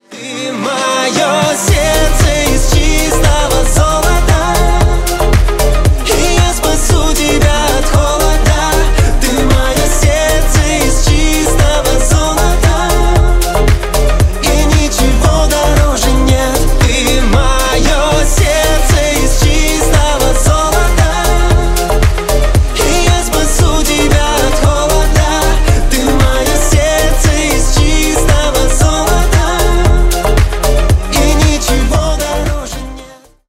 Ремикс # Танцевальные